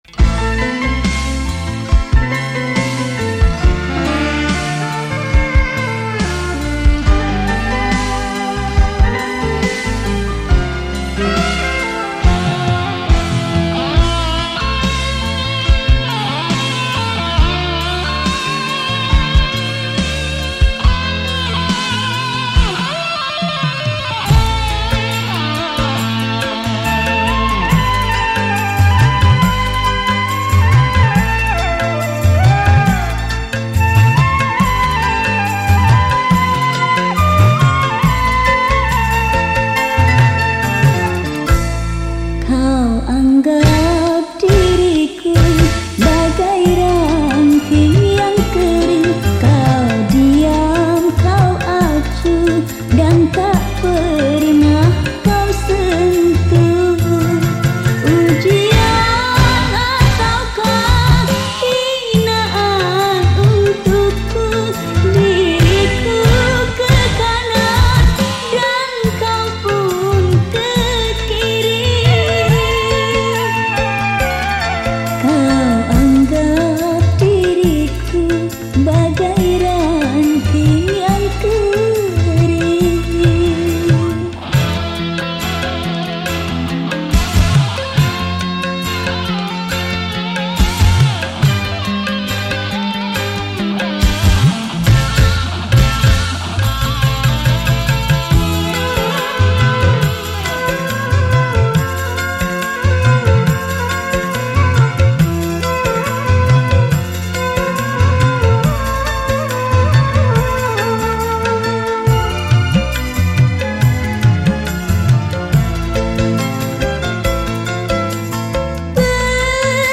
Instrumen                                     : Vokal